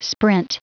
Prononciation du mot sprint en anglais (fichier audio)
Prononciation du mot : sprint